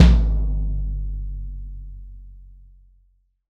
Index of /90_sSampleCDs/AKAI S6000 CD-ROM - Volume 3/Kick/GONG_BASS
GONG BASS1-S.WAV